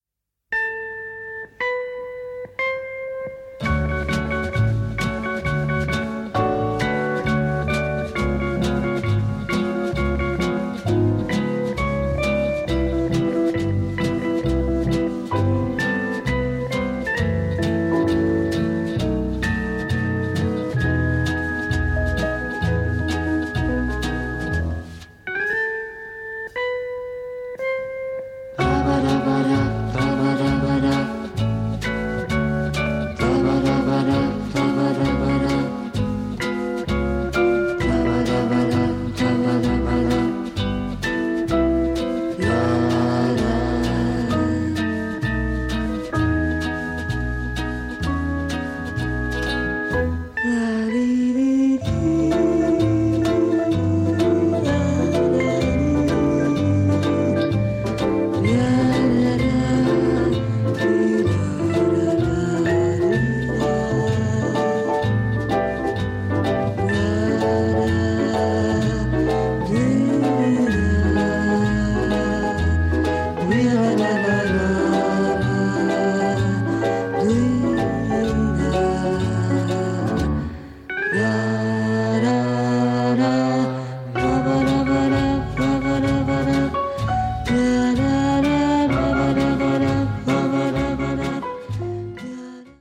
the melodies are stunningly beautiful.